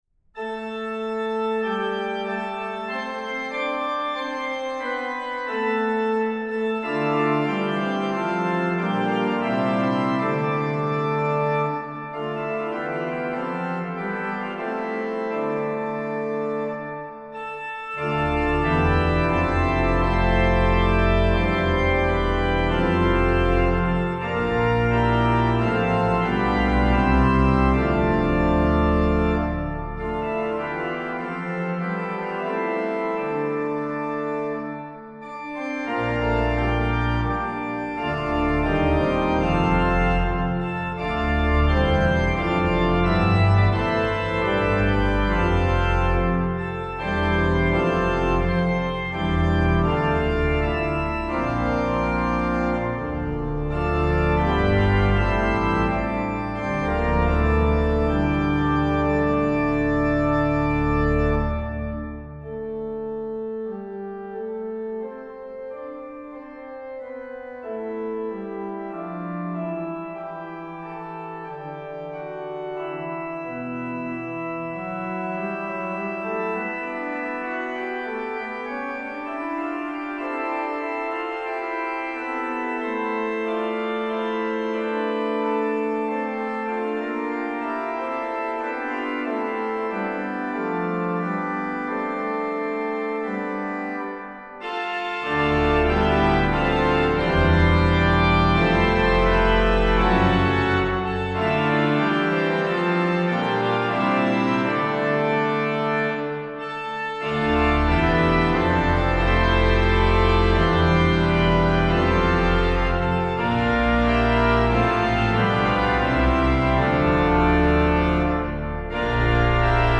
Voicing: Org 2-staff